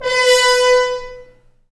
Index of /90_sSampleCDs/Roland L-CDX-03 Disk 2/BRS_F.Horns FX+/BRS_FHns Mutes